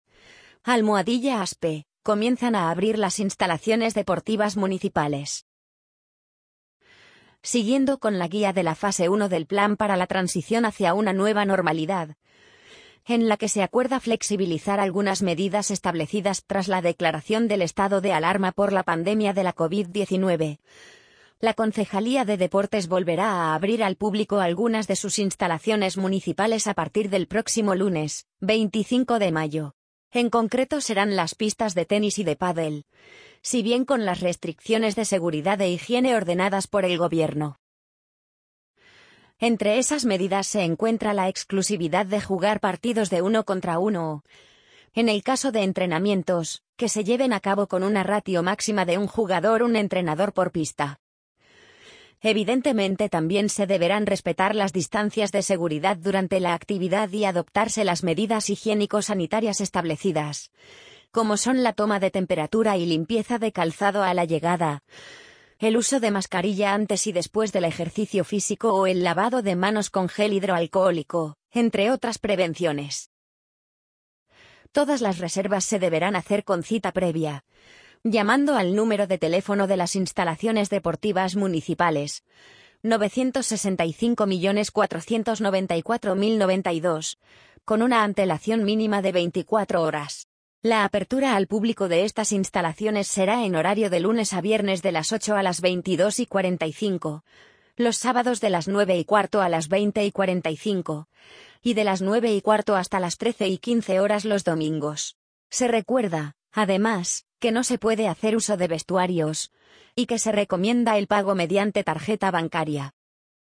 amazon_polly_42918.mp3